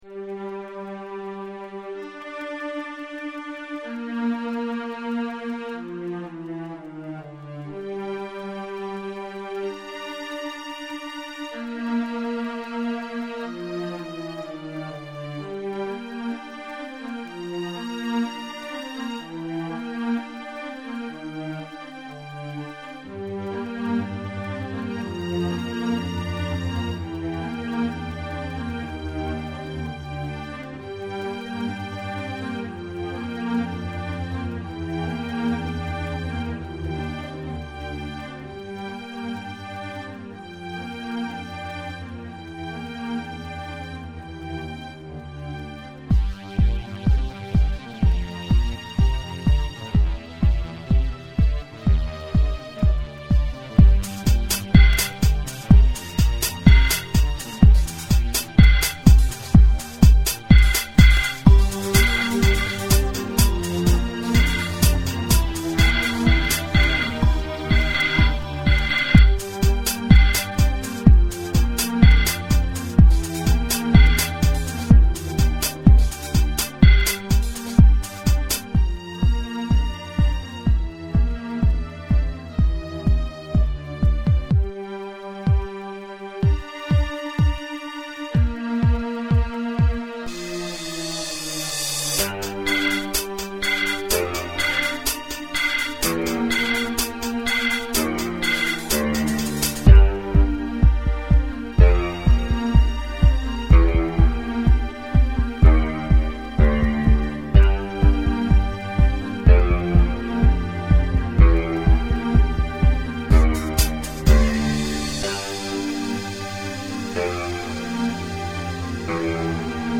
Here you will find some rough recording clips of music I've been working on, mostly recently but some from when I was a teenager in the late 90s/early 2000s.
Electronic Music
These are a few songs I made in my bedroom around 1999ish as a teenager.